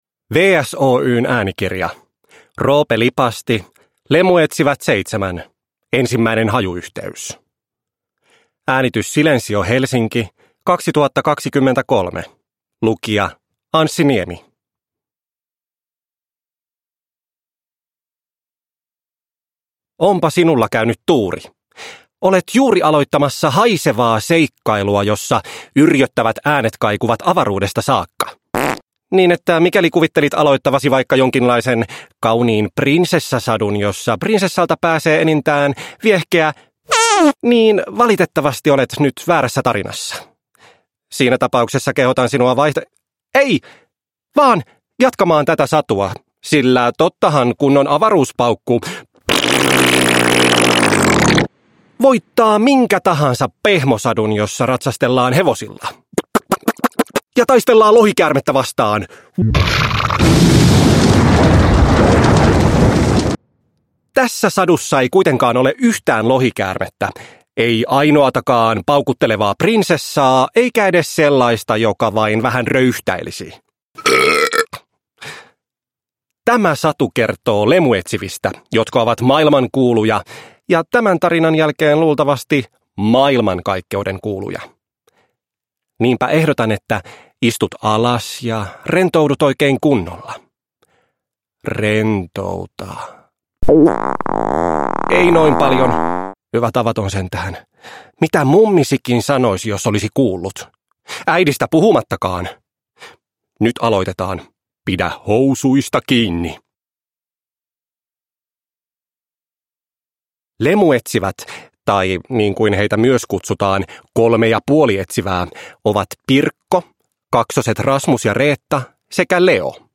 Lemuetsivät 7: Ensimmäinen hajuyhteys – Ljudbok – Laddas ner